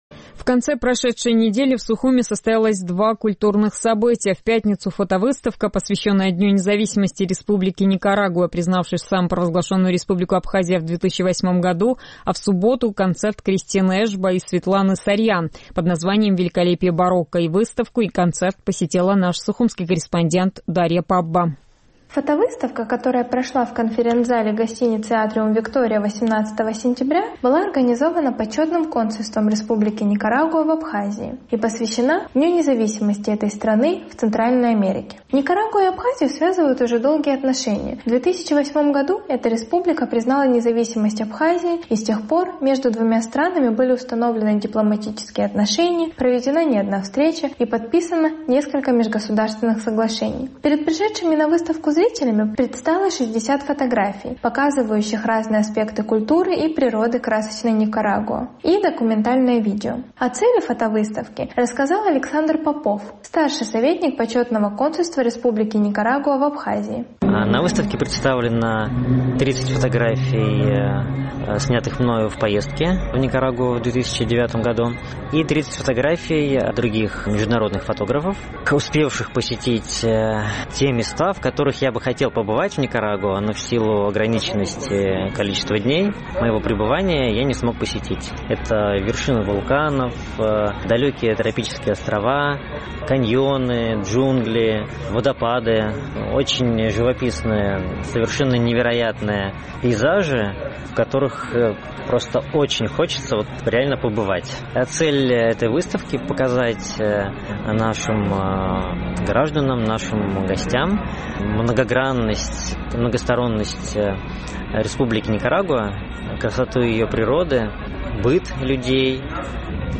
На следующий после фотовыставки день в Малом зале Абхазской государственной филармонии имени Раждена Гумба прошел концерт «Великолепие барокко».